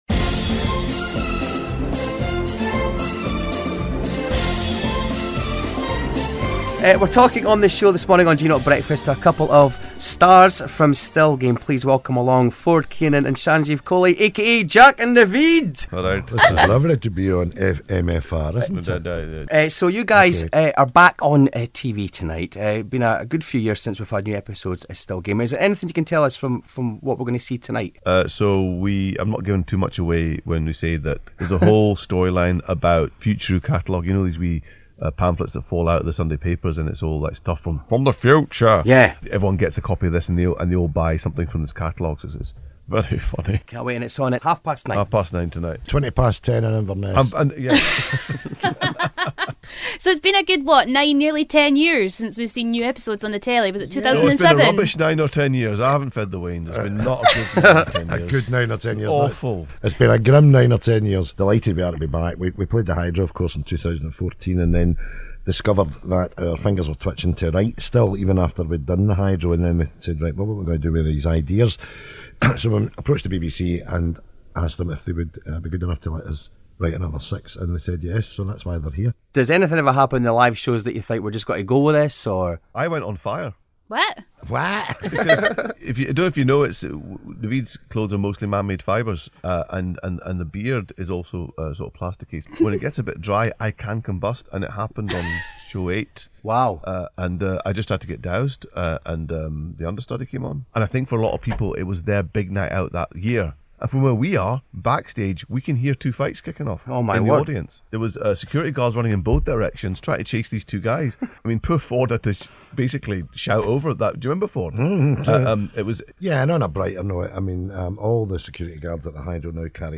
Still Game Interview - Jack and Navid